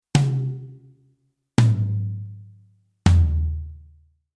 Innen-Mikrofonierung der Toms
Ein fest installiertes Mikrofon in einer Trommel bedeutet optimaler Schutz gegen Übersprechen durch andere Signalquellen.